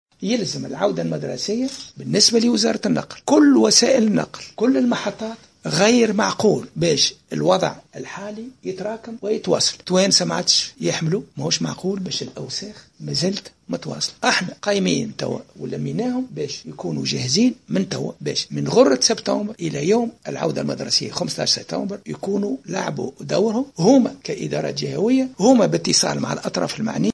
Ecoutez son intervention محمود بن رمضان t√©l√©charger partager sur